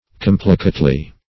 complicately - definition of complicately - synonyms, pronunciation, spelling from Free Dictionary Search Result for " complicately" : The Collaborative International Dictionary of English v.0.48: Complicately \Com"pli*cate*ly\, adv.
complicately.mp3